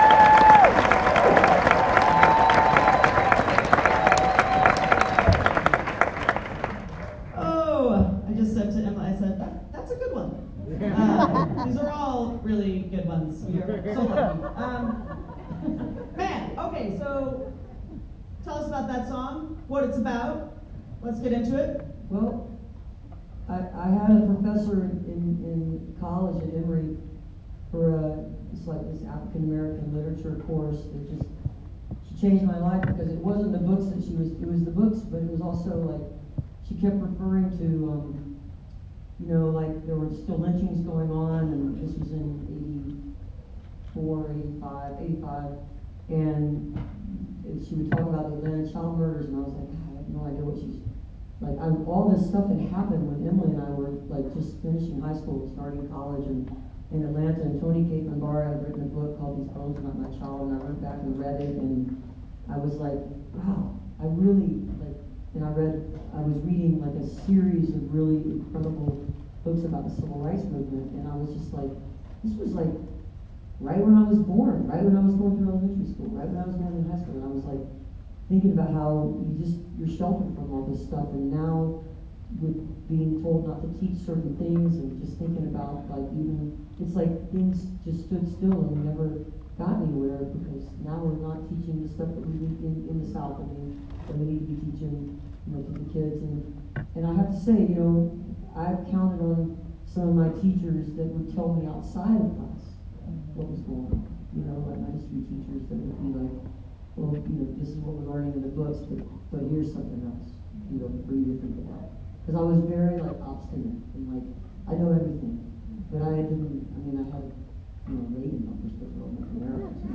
04. interview (3:33)